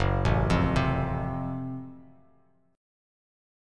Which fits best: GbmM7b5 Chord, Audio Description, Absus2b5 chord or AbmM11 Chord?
GbmM7b5 Chord